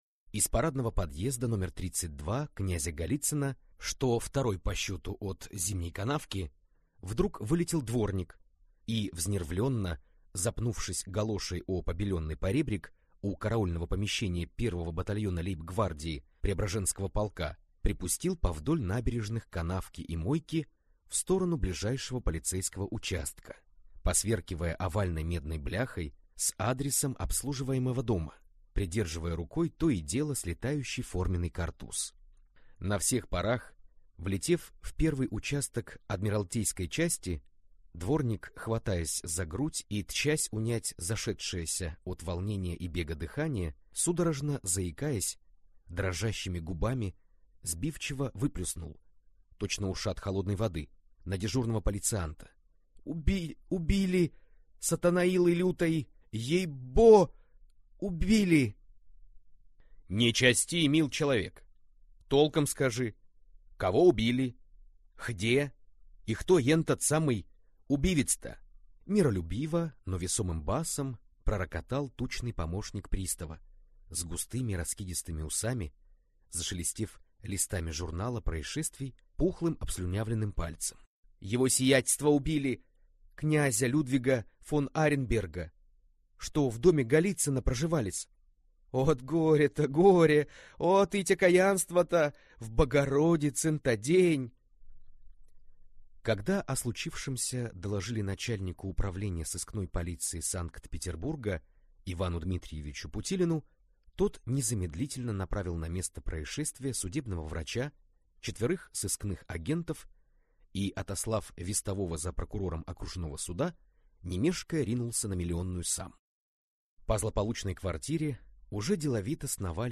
Аудиокнига Всему наперекор. Пасхальный перформанс операции «Карамболь» | Библиотека аудиокниг